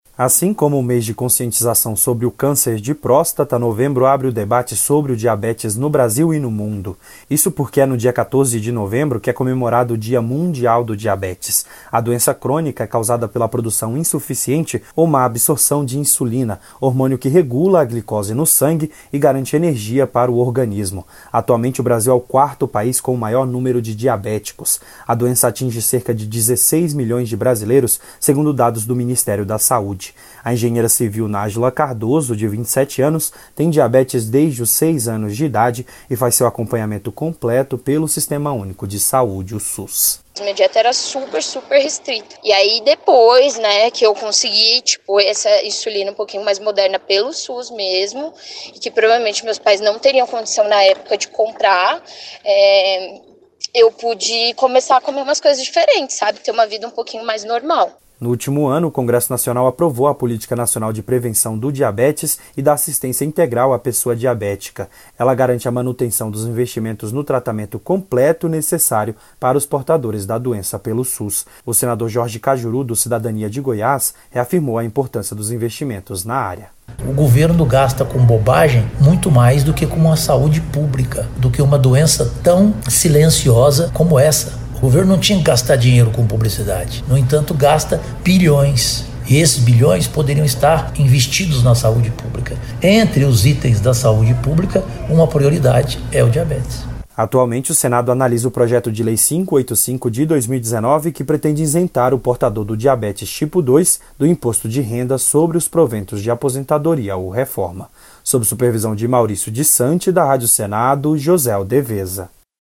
No último ano, o Congresso Nacional aprovou a Política Nacional de Prevenção do Diabetes e da Assistência Integral à Pessoa Diabética, que garante a manutenção dos investimentos no tratamento completo para os doentes. O senador Jorge Kajuru (Cidadania-GO) defende mais investimentos na melhoria do tratamento da doença no Sistema Único de Saúde (SUS). A reportagem